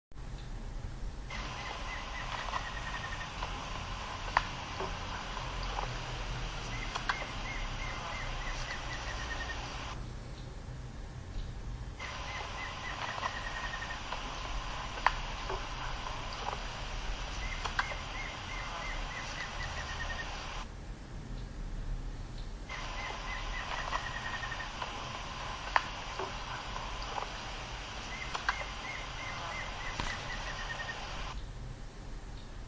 Plain Antvireo (Dysithamnus mentalis)
Life Stage: Adult
Location or protected area: Parque Nacional Iguazú
Detailed location: Sendero Macuco
Condition: Wild
Certainty: Recorded vocal